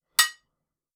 tap.wav